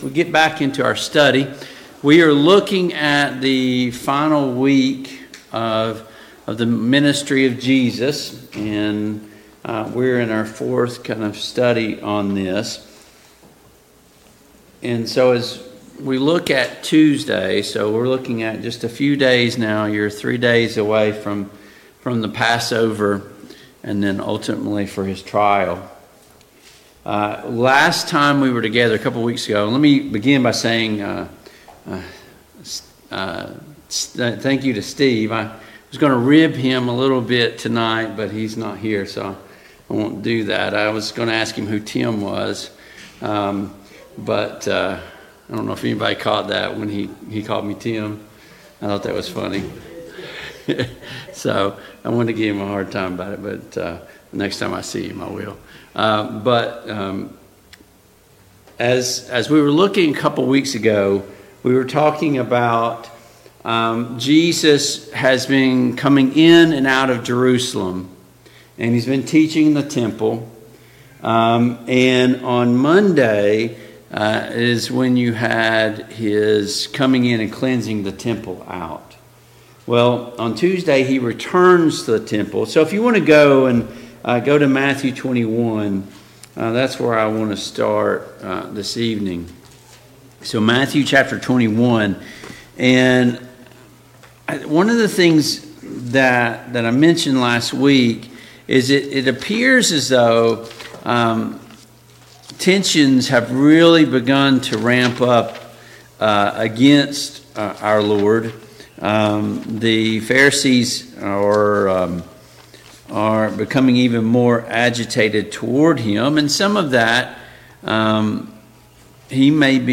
Service Type: Mid-Week Bible Study Download Files Notes Topics: The Temple , The Temple Cleansing « The Temple of God 6.